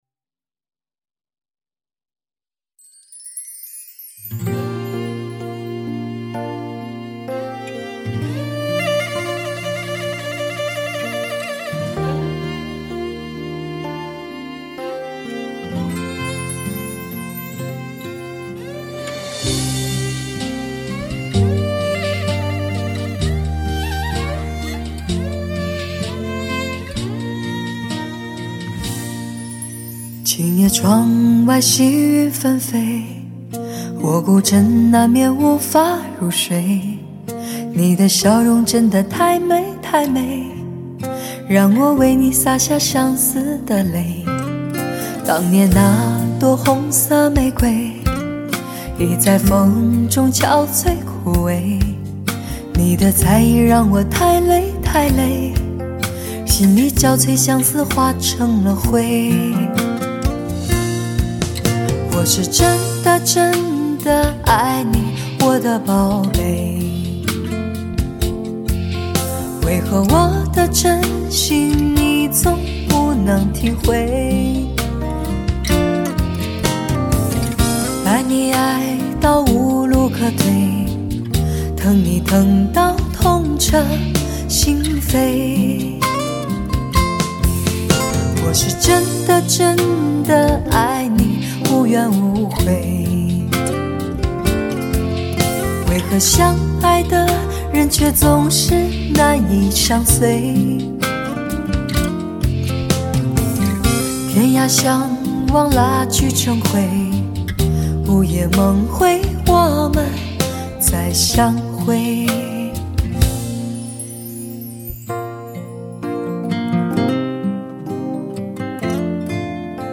最具诱惑力的发烧音色，让你感受超完全无法抵御的声色魅力！
顶级发烧器材专业监听产品，至尊享受并超越完美音质的顶级发烧大碟
顶级最具视听效果的发烧原音，试机宝贝中的典范！